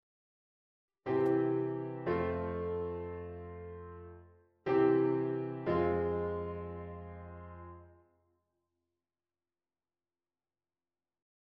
'opspringende' leidtoon